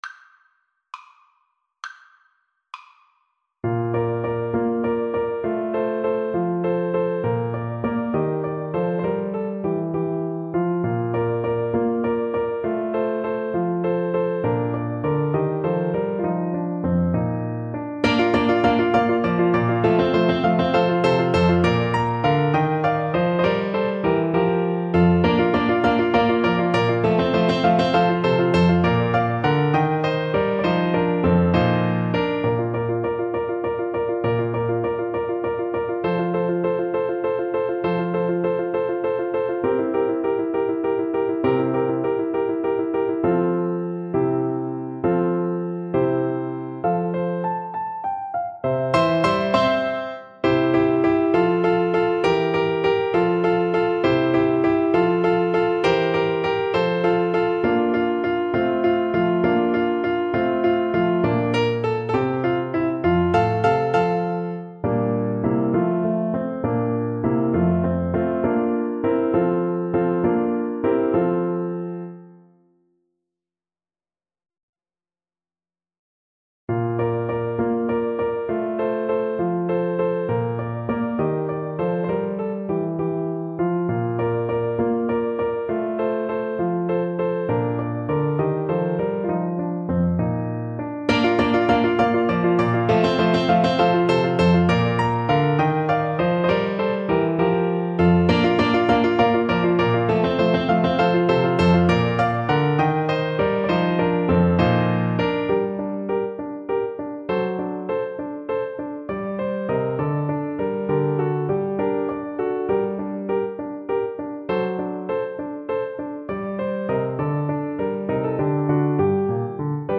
Rondo
6/8 (View more 6/8 Music)
Classical (View more Classical Clarinet Music)